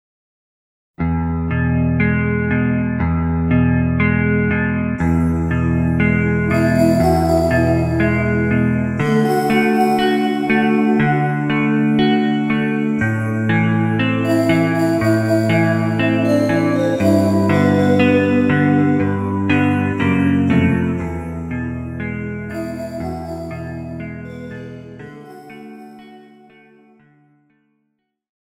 Žánr: Rock
BPM: 60
Key: E
MP3 ukázka